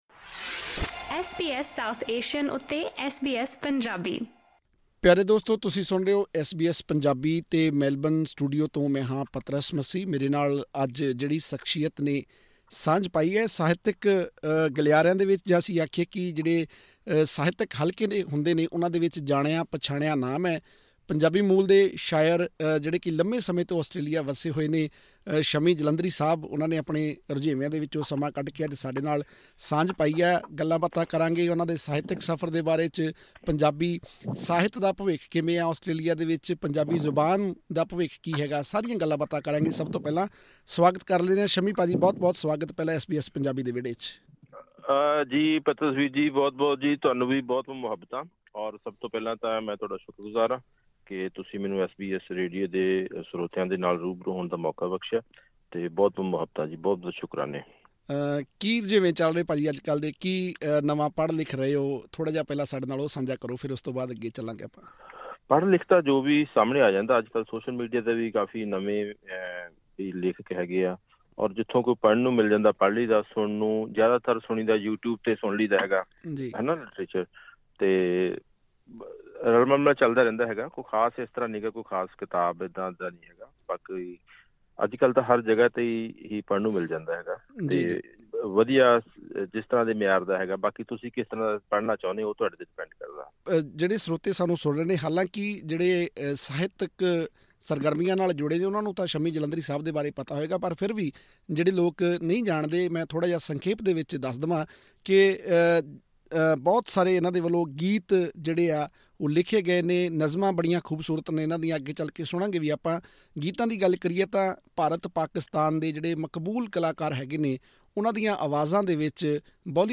ਹੋਰ ਵੇਰਵੇ ਲਈ ਸੁਣੋ ਇਹ ਗੱਲਬਾਤ...